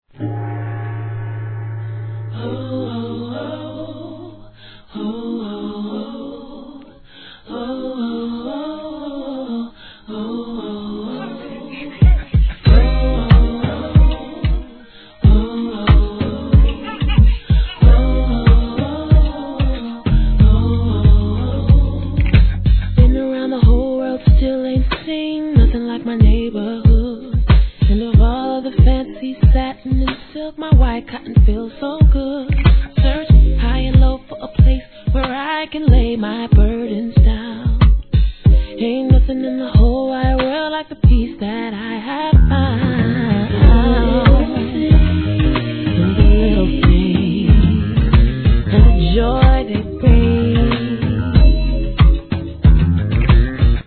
HIP HOP/R&B
2002年、本作も期待通りアーバンで心地よい極上のオーガニック・ソウル♪